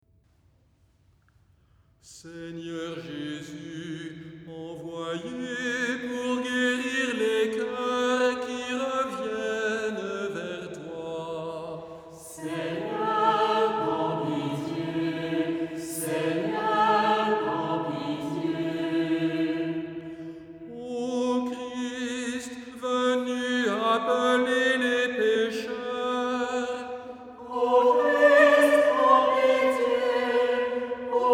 Si l’on dispose d’un petit groupe-choral (3 voix mixtes), ces pièces peuvent être chantées en polyphonie.
La troisième forme de l’Acte pénitentiel présente des « tropes » mesurés qui sont chantés, soit par le prêtre, soit par un chantre ou un petit-groupe choral. L’intercession est chantée une première fois par un chantre ou un petit groupe-choral et ensuite reprise par l’assemblée.